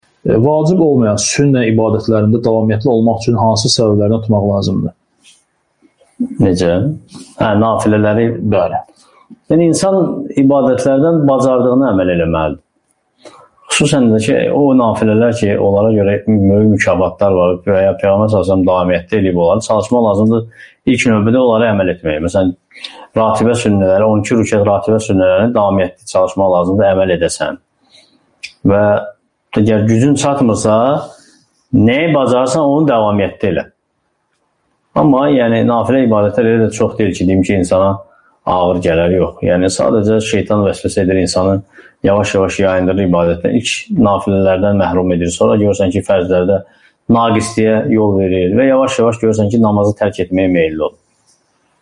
Namaz (SUAL-CAVAB)